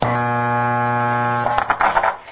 When you hear someone casting an electrical spell followed by the sound of 60 cycle hum and then the sound of rubbing voice coils, that means it's already too late!
background sound is the sound of what happens when you rely entirely on Witchcraft to get things done!